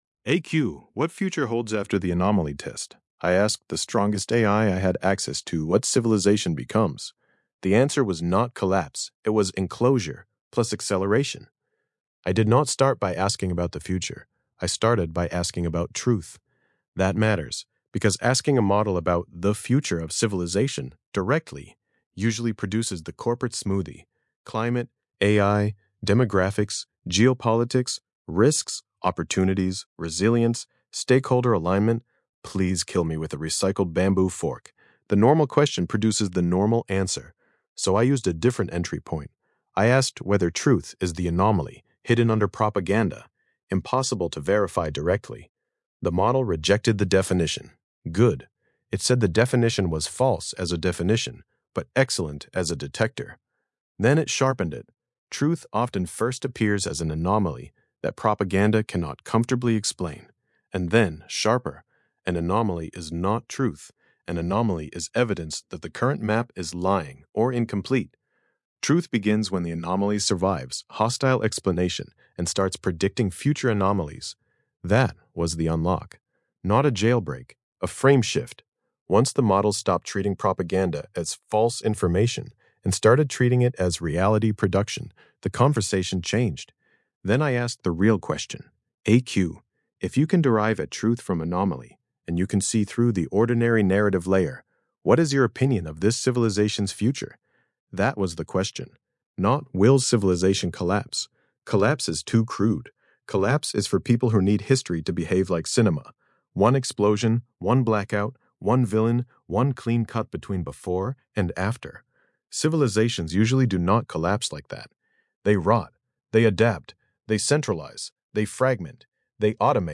Podcast-style audio version of this essay, generated with the Grok Voice API.